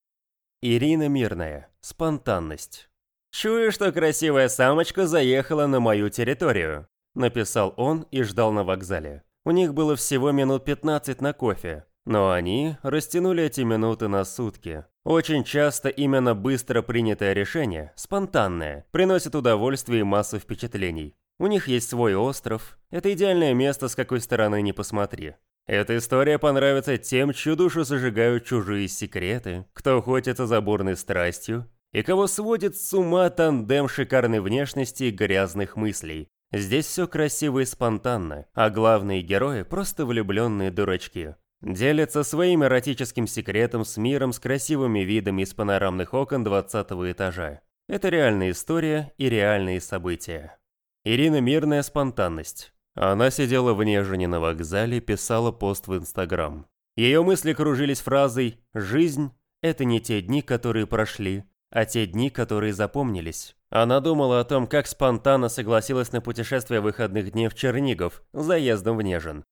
Aудиокнига Спонтанность